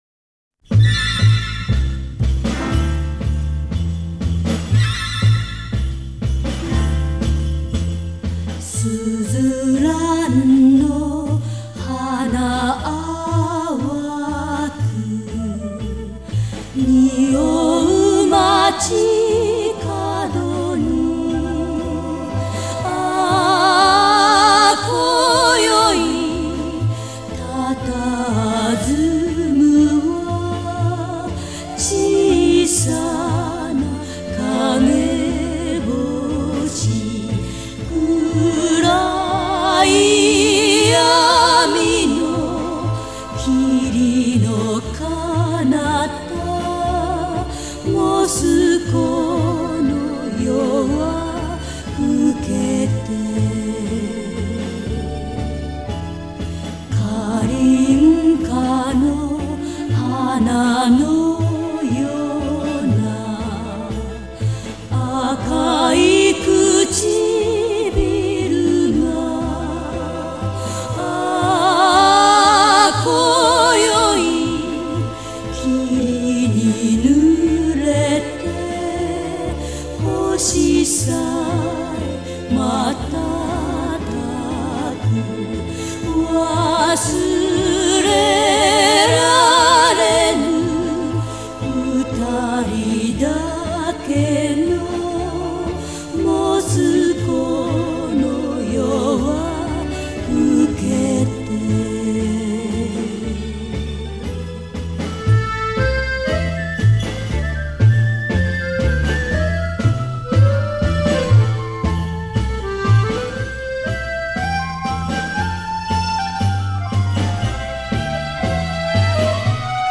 Звук неплохой. Японский ремастеринг.
Жанр: Classic Pop